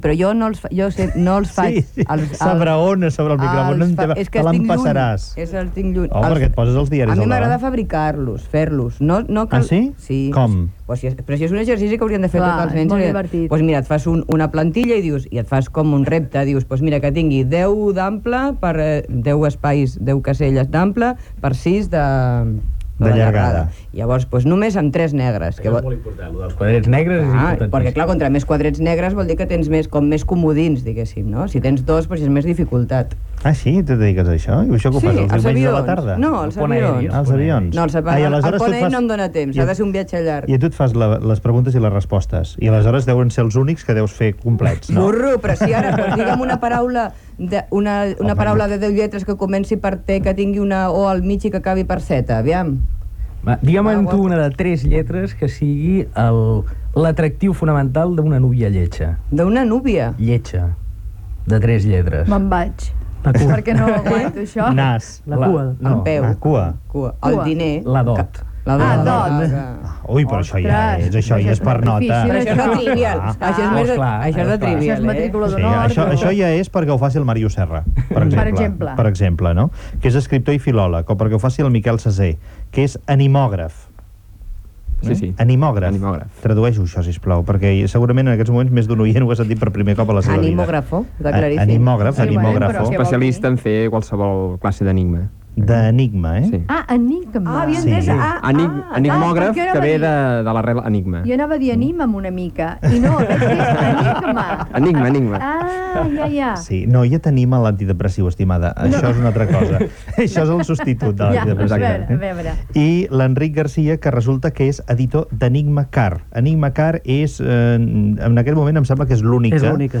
Els mots encreuats, entrevista
Gènere radiofònic Info-entreteniment